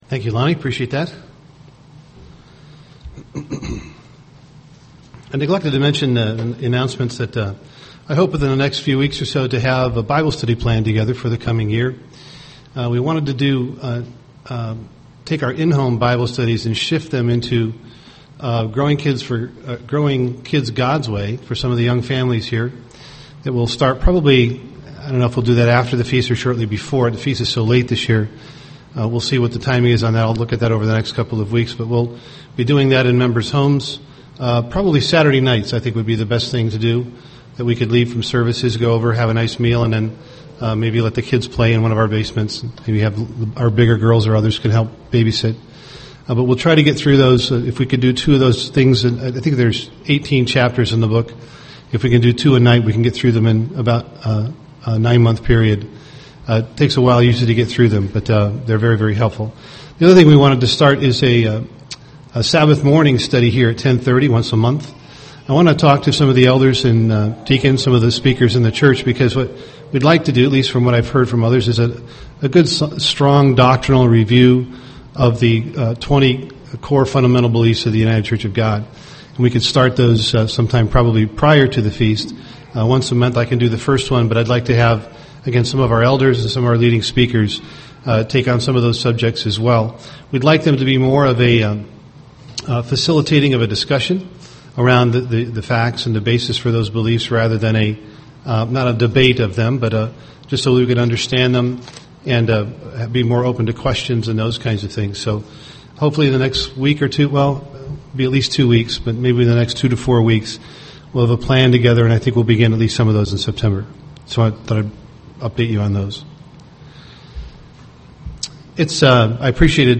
UCG Sermon marriage marriage covenant Marriage and Family Transcript This transcript was generated by AI and may contain errors.